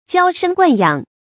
注音：ㄐㄧㄠ ㄕㄥ ㄍㄨㄢˋ ㄧㄤˇ
嬌生慣養的讀法